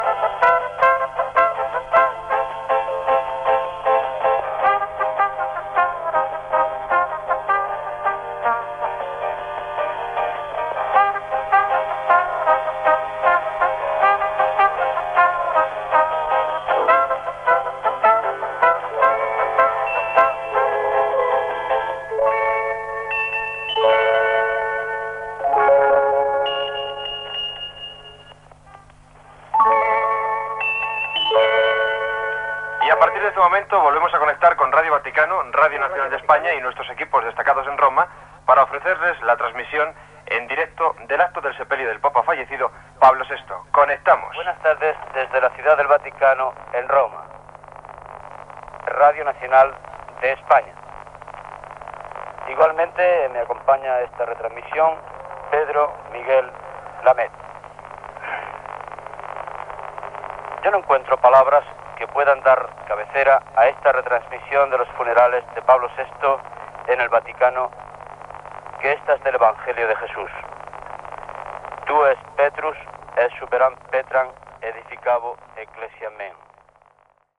Tema musical, sintonia de Ràdio Sabadell, connexió amb Radio Nacional de España per oferir, des de la Ciutat del Vaticà, el funeral del Sant Pare Pau VI (Giovanni Battista Maria Montini)
Informatiu